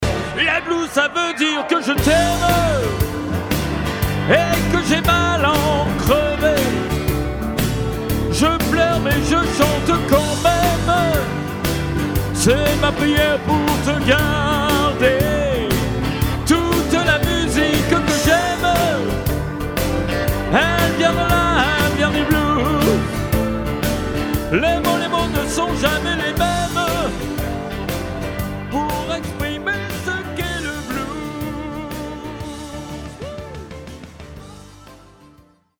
Version chantée